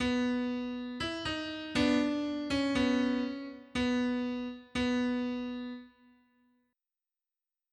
• Качество: 321, Stereo
без слов
клавишные
пианино